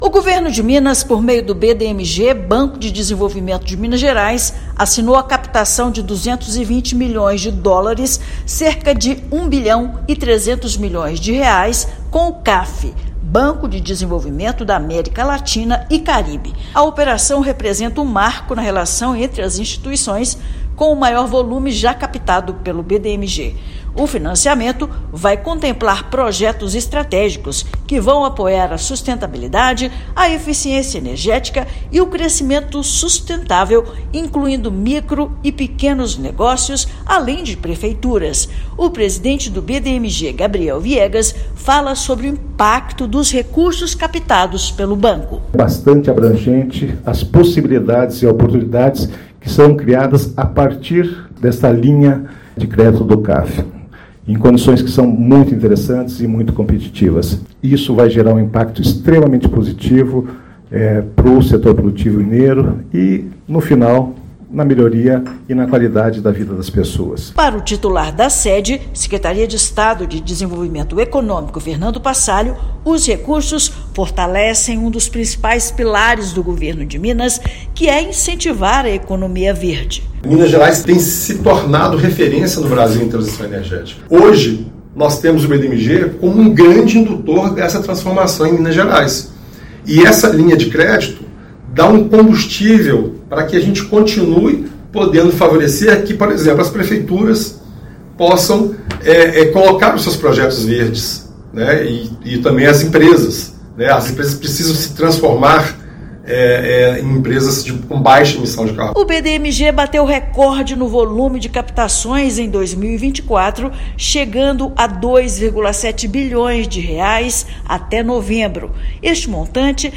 Volume é o maior já captado pelo banco mineiro e o primeiro no modelo A/B Loan com a participação de outras seis instituições. Ouça matéria de rádio.